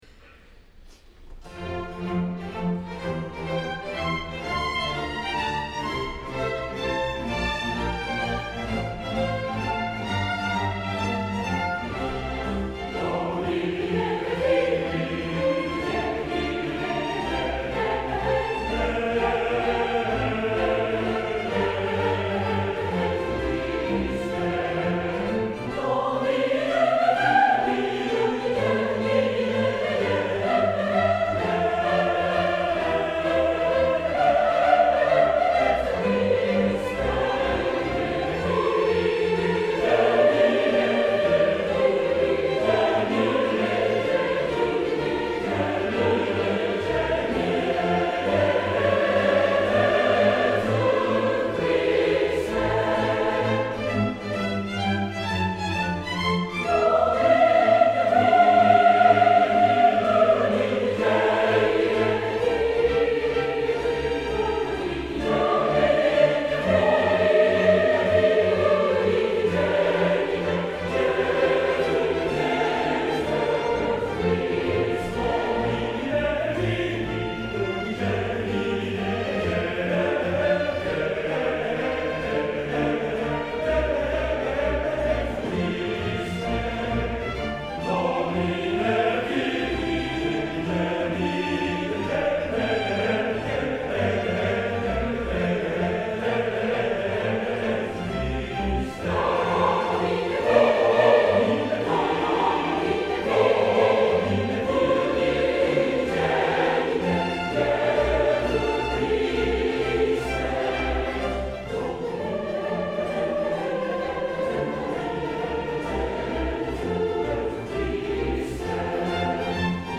第６２回定期演奏会
２００２年６月１６日　東京文化会館大ホール